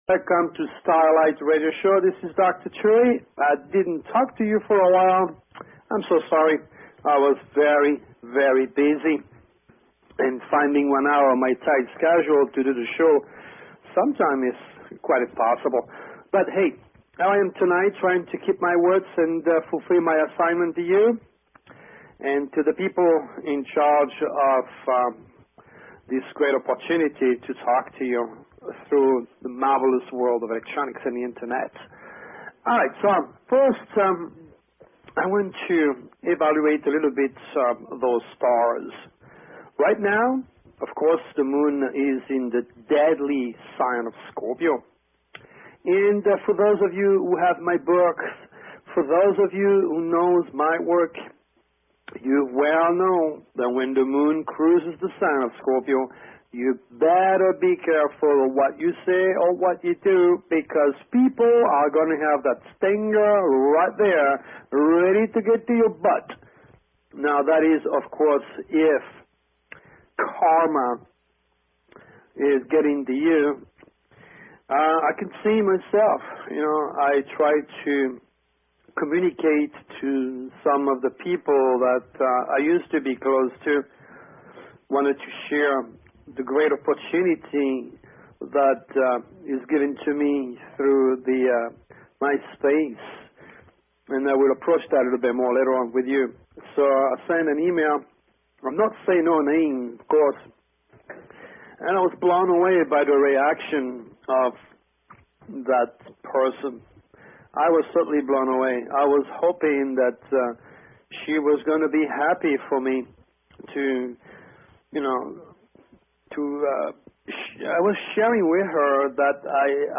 Talk Show Episode, Audio Podcast, Starlight_Radio and Courtesy of BBS Radio on , show guests , about , categorized as
Courtesy of BBS Radio